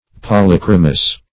polychromous - definition of polychromous - synonyms, pronunciation, spelling from Free Dictionary
Search Result for " polychromous" : The Collaborative International Dictionary of English v.0.48: Polychromous \Pol`y*chro"mous\, a. Of or pertaining to polychromy; many-colored; polychromatic.